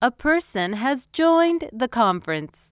conference_join.wav